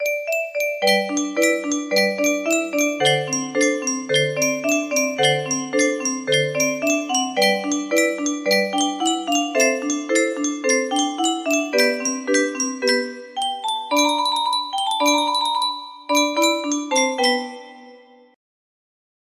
Down by the Bay music box melody
Little arrangement of one of my favorite nursery rhymes, Down by the Bay